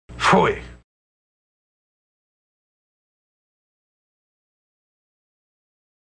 pfui audio  Maury Chaykin's pronunciation #2
Pfui_Chaykin2.mp3